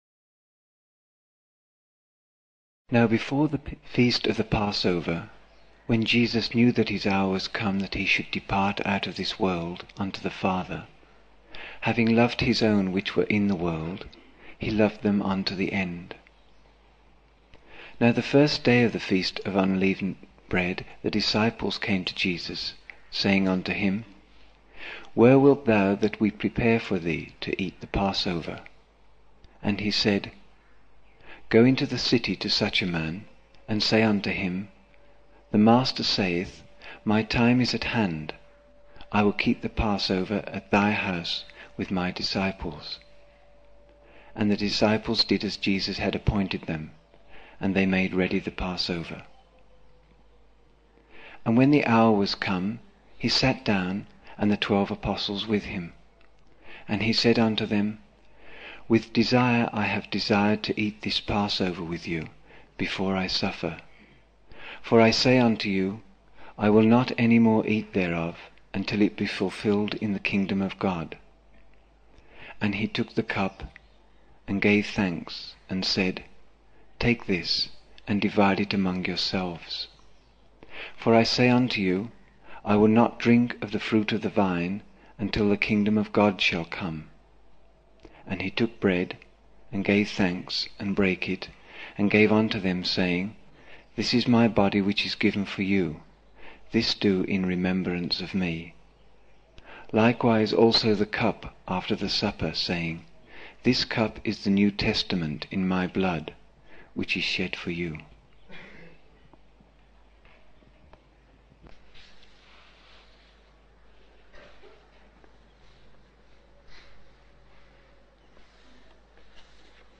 21 October 1975 morning in Buddha Hall, Poona, India